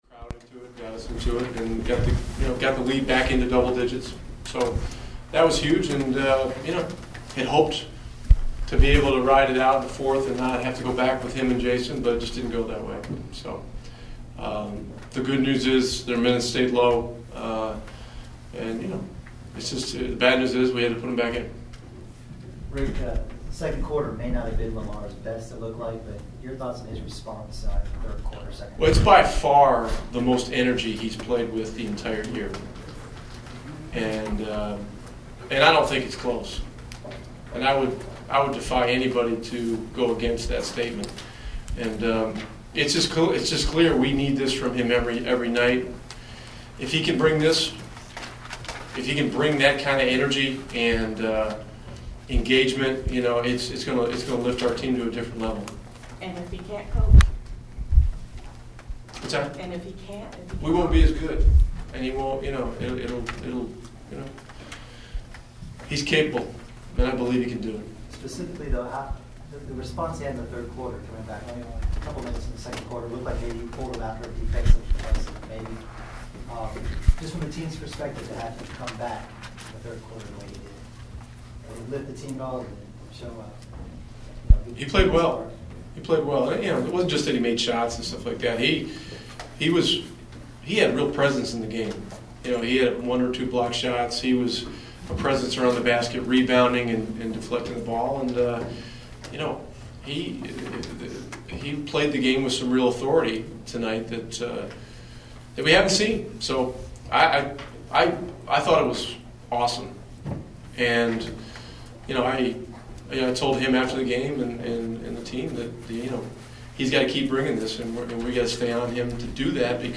LISTEN: Coach Rick Carlisle post game interview (iPad/iPhone - click here)